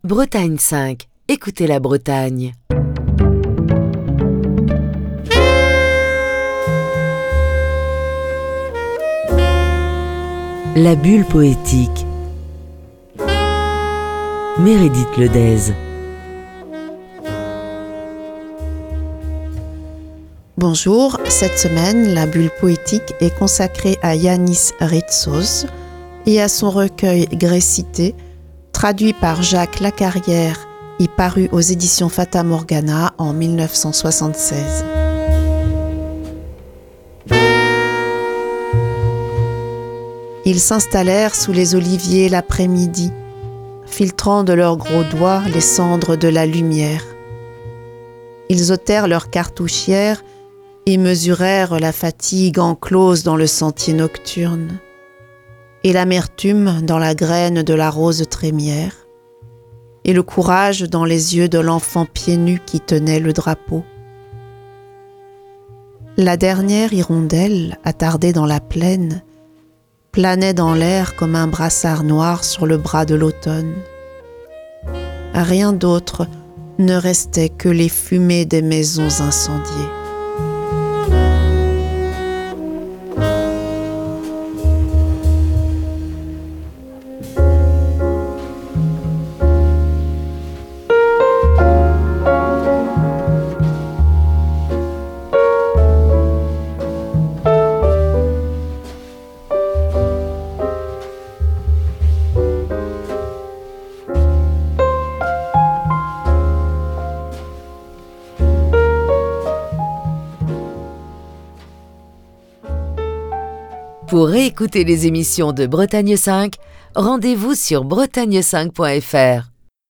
lit cette semaine des textes du poète grec Yánnis Rítsos